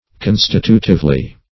Constitutively \Con"sti*tu`tive*ly\, adv. In a constitutive manner.